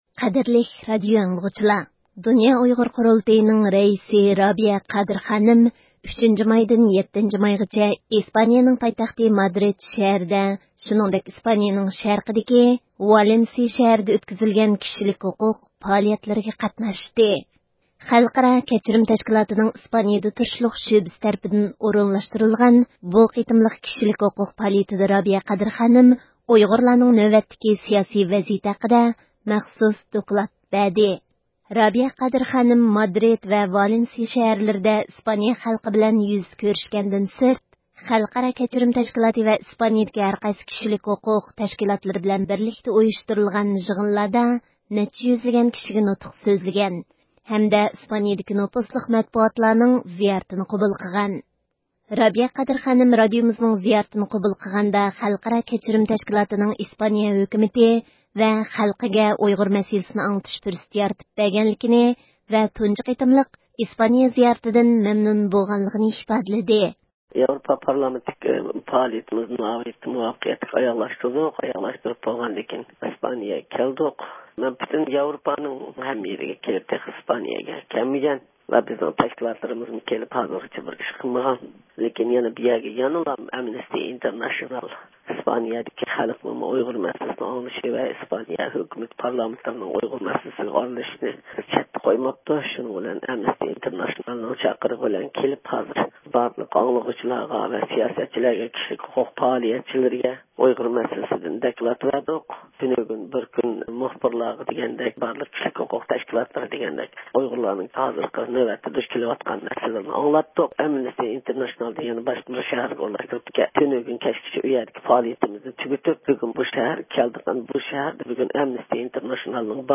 رابىيە قادىر خانىم، رادىئومىزنىڭ زىيارىتىنى قوبۇل قىلغاندا، خەلقئارا كەچۈرۈم تەشكىلاتىنىڭ، ئىسپانىيە ھۆكۈمىتى ۋە خەلقىگە ئۇيغۇر مەسىلىسىنى ئاڭلىتىش پۇرسىتى يارىتىپ بەرگەنلىكىنى ۋە تۇنجى قېتىملىق ئىسپانىيە زىيارىتىدىن مەمنۇن بولغانلىقىنى ئىپادىلىدى.